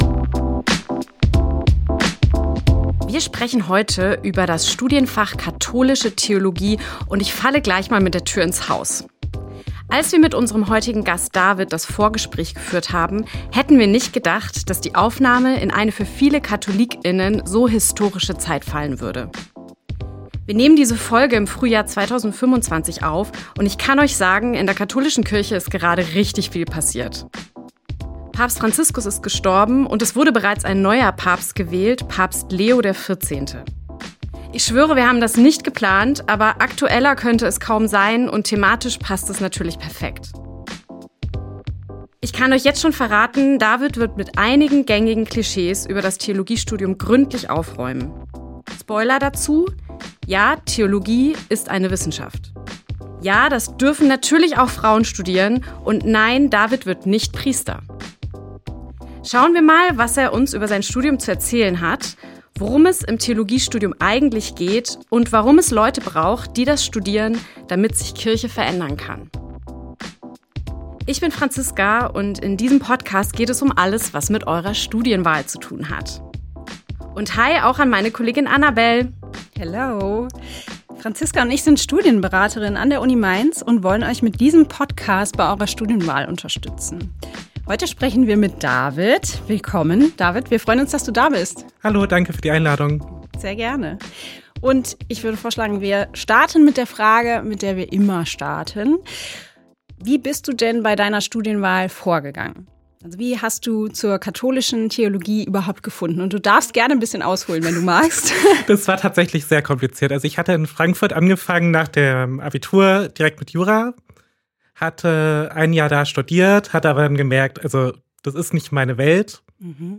Beschreibung vor 7 Monaten Wir sind zurück aus der Sommerpause und sprechen in dieser Folge über das Studienfach Katholische Theologie. Unser Gast